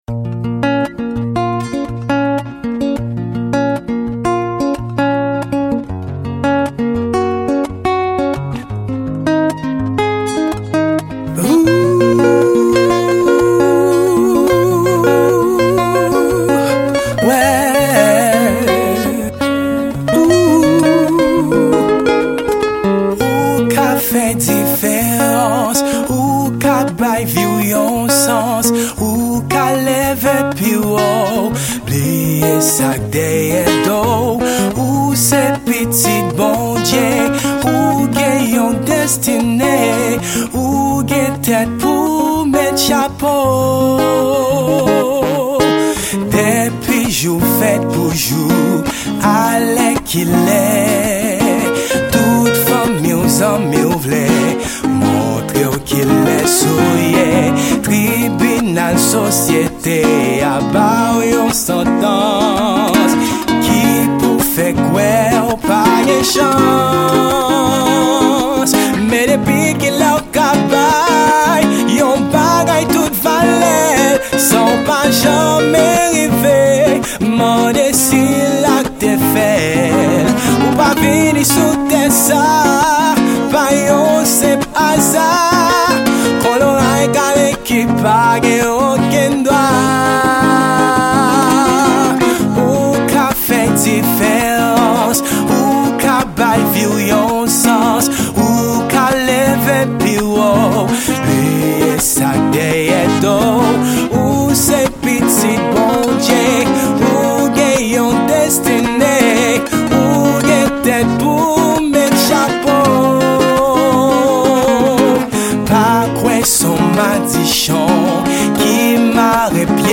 Genre: World Music.